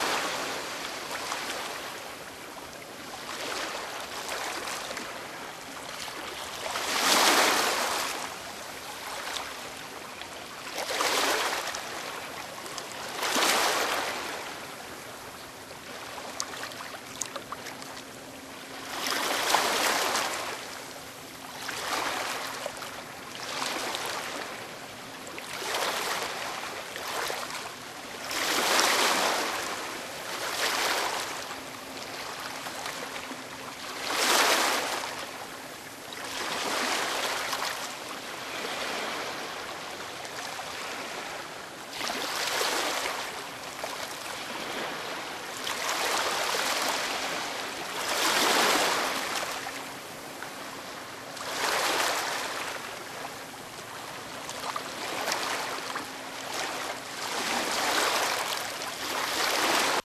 waterfall.ogg